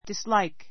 dislike A2 disláik ディ ス ら イ ク 動詞 嫌 きら う, いやがる ⦣ dis- not ＋like （好きである）.